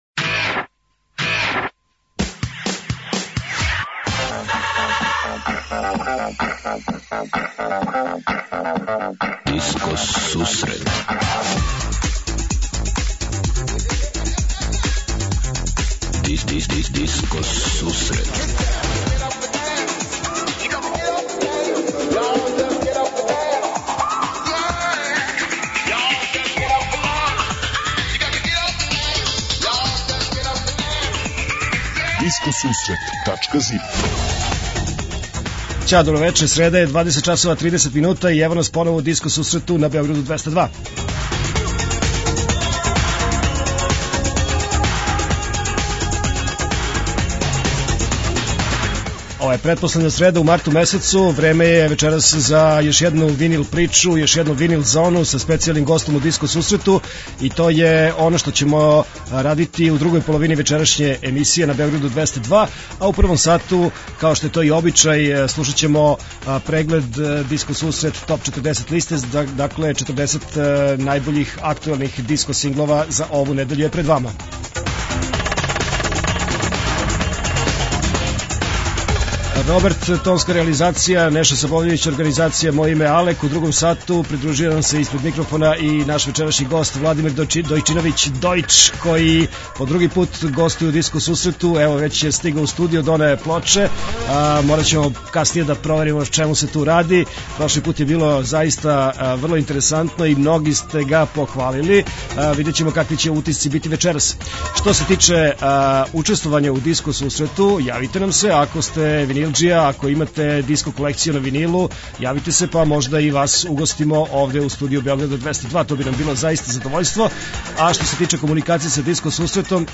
Од 20:30 Диско Сусрет Топ 40 - Топ листа 40 највећих светских диско хитова.
21:30 Винил Зона - Слушаоци, пријатељи и уредници Диско Сусрета за вас пуштају музику са грамофонских плоча.
преузми : 28.62 MB Discoteca+ Autor: Београд 202 Discoteca+ је емисија посвећена најновијој и оригиналној диско музици у широком смислу, укључујући све стилске утицаје других музичких праваца - фанк, соул, РнБ, итало-диско, денс, поп.